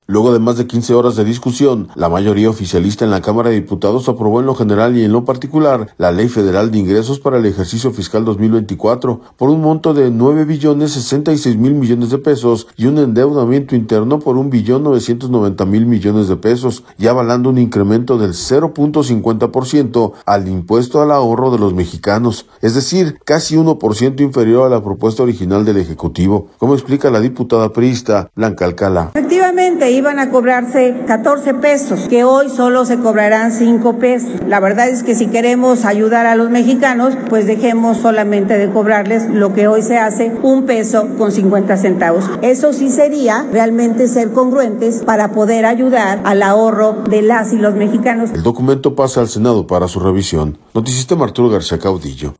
Luego de más de 15 horas de discusión, la mayoría oficialista en la Cámara de Diputados aprobó en lo general y en lo particular, la Ley Federal de Ingresos para el ejercicio fiscal 2024, por un monto de 9 billones 66 mil millones de pesos y un endeudamiento interno por un billón 990 mil millones de pesos y avalando un incremento del 0.50 por ciento al impuesto al ahorro de los mexicanos, es decir, casi uno por ciento inferior a la propuesta original del Ejecutivo, como explica la diputada priista, Blanca Alcalá.